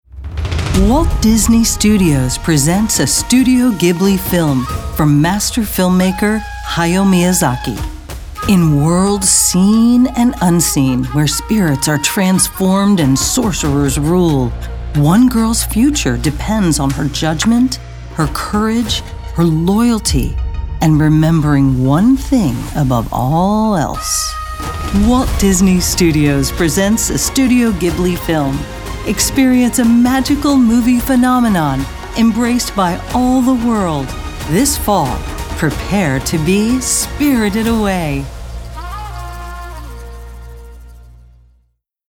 anti-announcer, conversational, friendly, inspirational, motivational, movie-trailer, promo, soccer mom, thoughtful, upbeat, warm